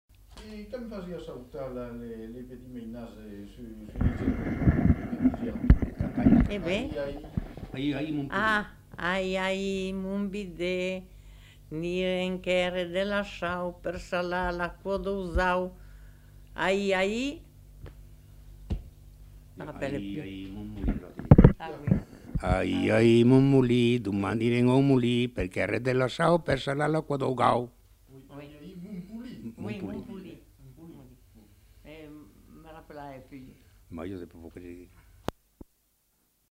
Aire culturelle : Périgord
Lieu : Saint-Rémy-de-Gurson
Type de voix : voix d'homme ; voix de femme
Production du son : récité
Classification : formulette enfantine
Notes consultables : D'abord dite par la femme puis par l'homme.